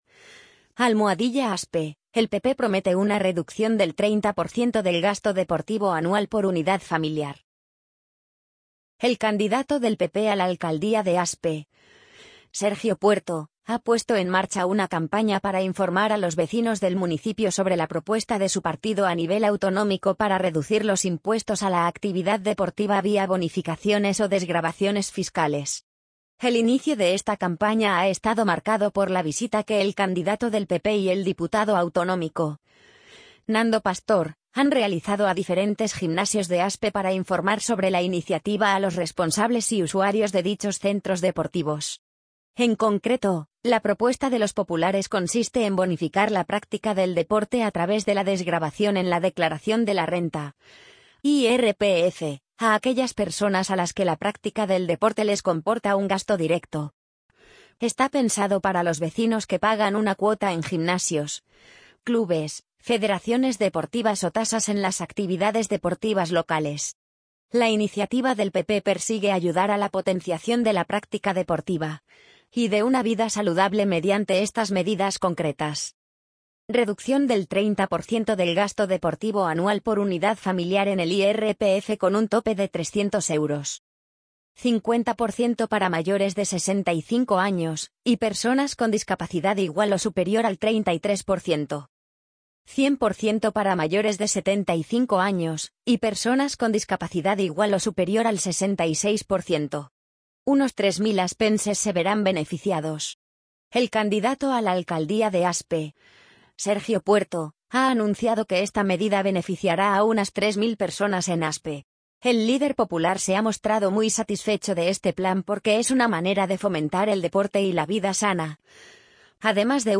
amazon_polly_65444.mp3